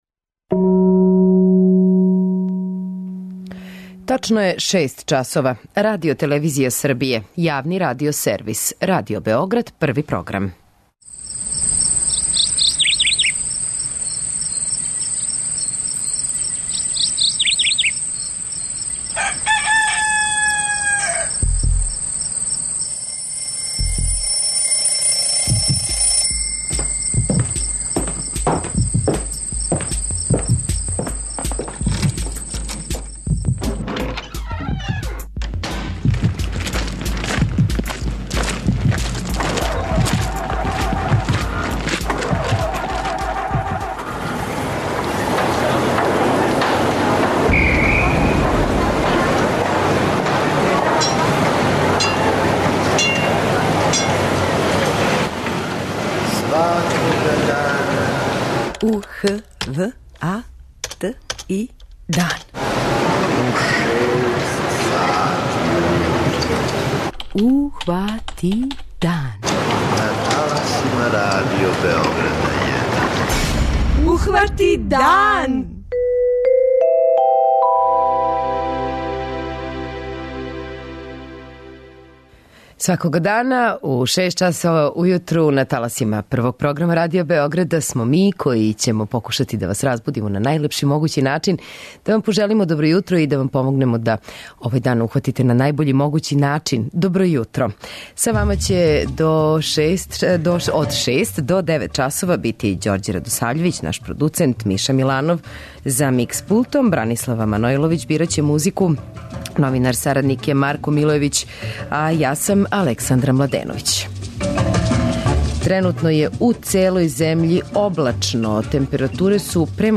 преузми : 85.96 MB Ухвати дан Autor: Група аутора Јутарњи програм Радио Београда 1!